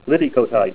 Name Pronunciation: Liddicoatite + Pronunciation Synonym: Liddicoatite - (61.3.1.2) Tourmaline Liddicoatite Image Images: Liddicoatite Comments: Dark green translucent liddicoatite crystal prism.